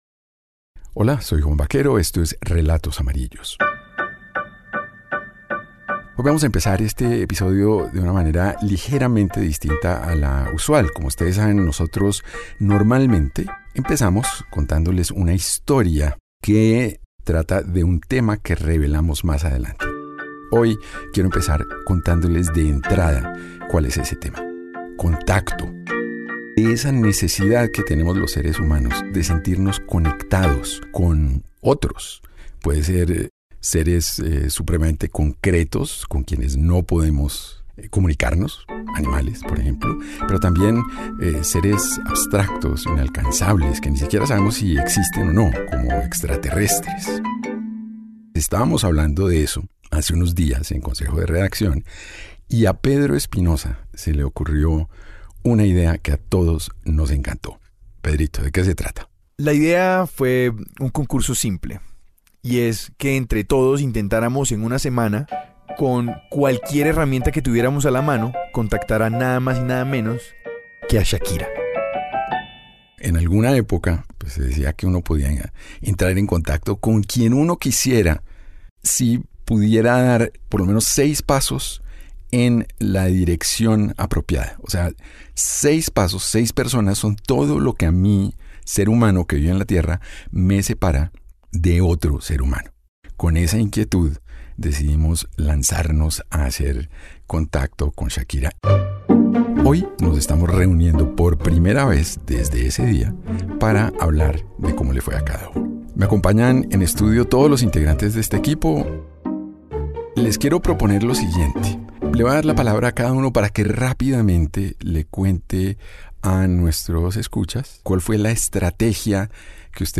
Escucha ahora la entrevista en Relatos amarillos, disponible en RTVCPlay.